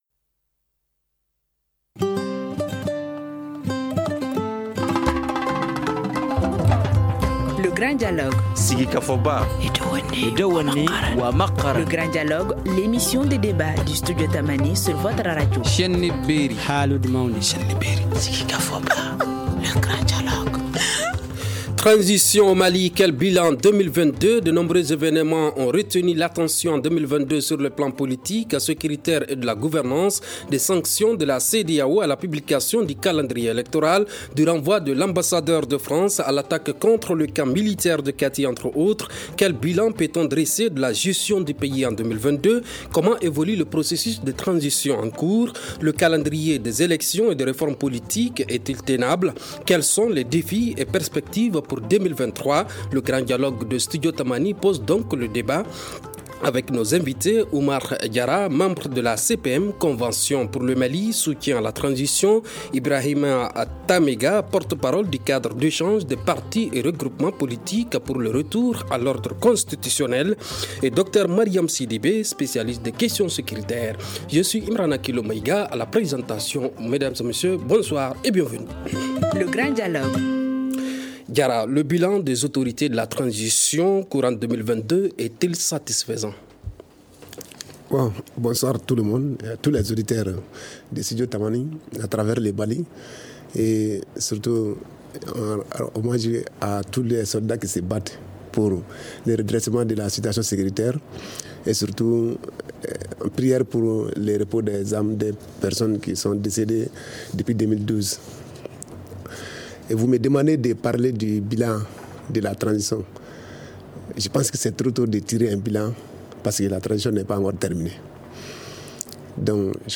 Le Grand Dialogue pose le débat avec nos invités.